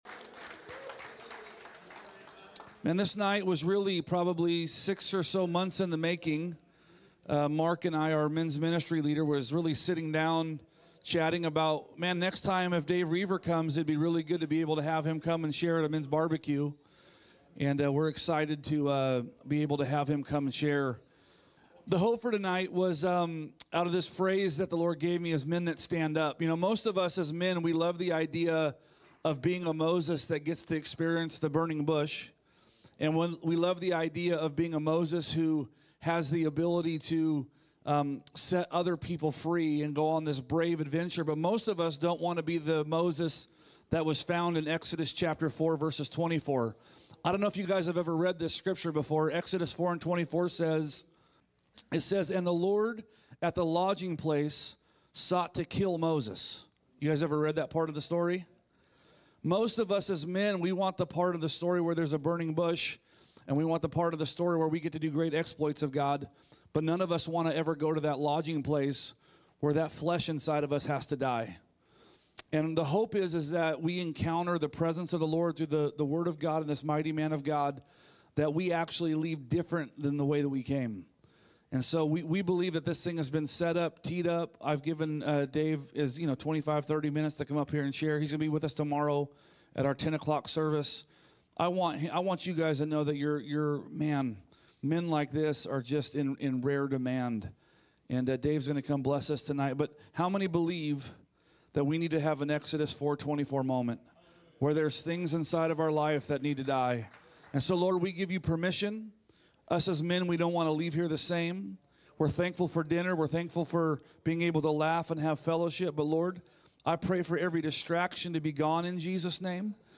A message from the series "Parkway Podcast."